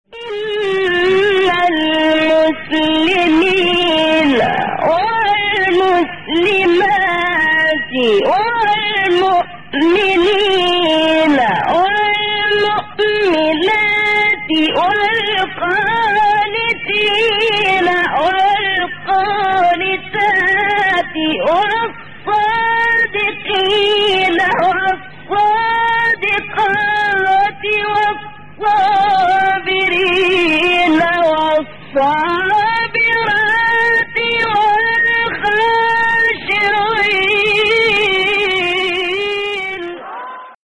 من هو هذا القارئ :